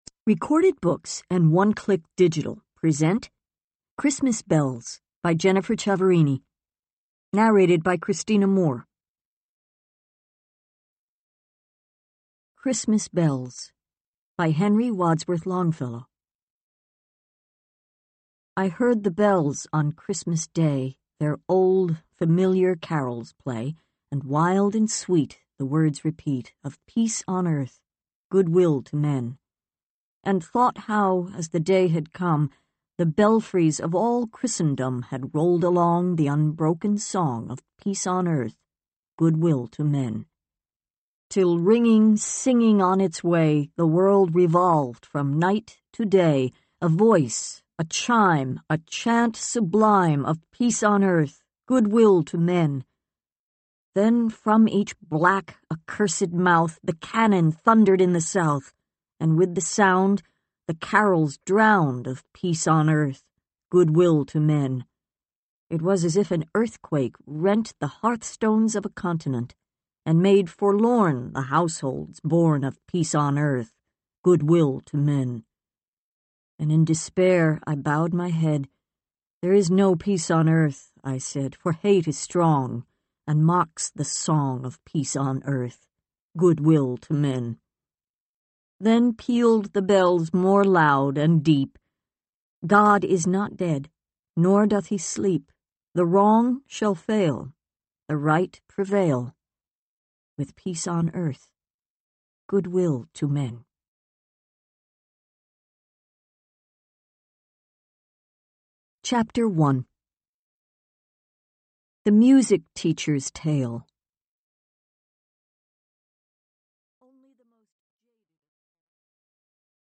digital digital digital stereo audio file Notes